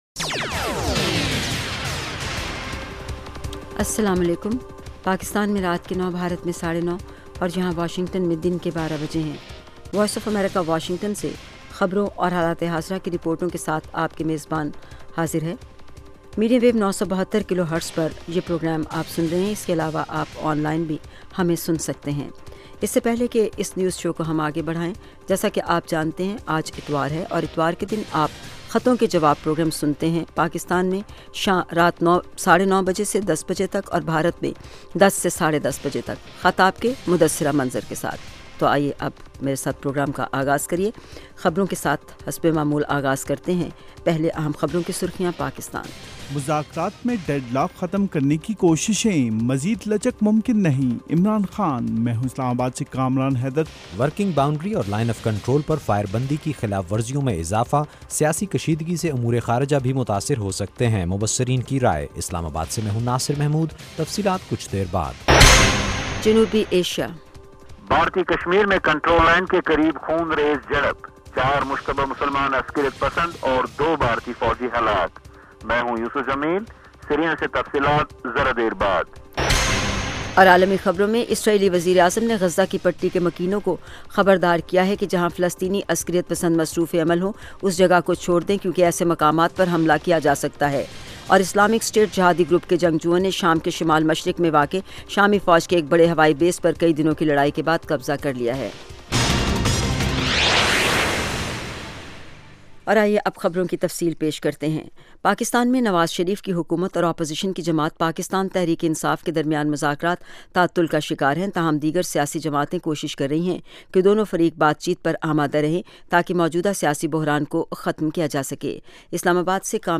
9:00PM اردو نیوز شو